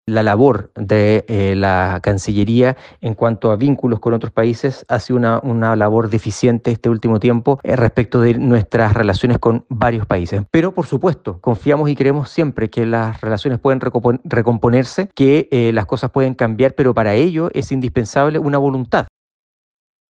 Por otro lado, el diputado republicano, Stephan Schubert, fue crítico con la Cancillería chilena y explicó que falta voluntad para mejorar la relación con países vecinos.